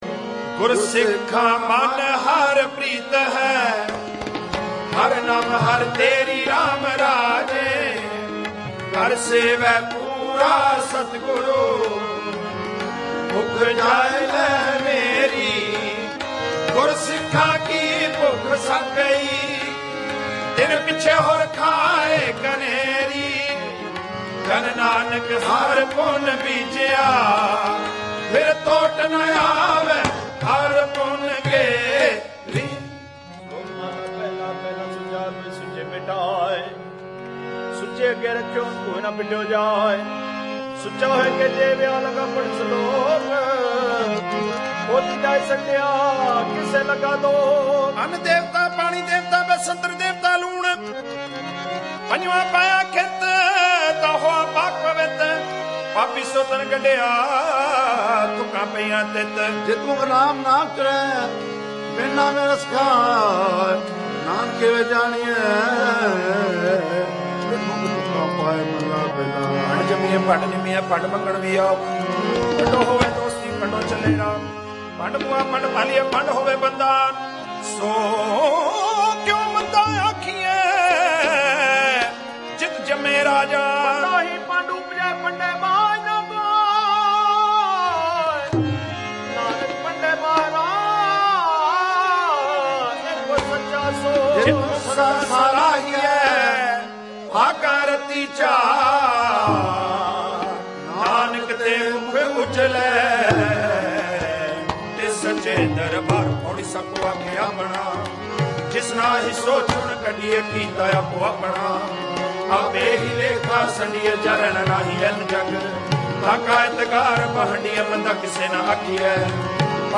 Genre: Paath (Kirtan Style)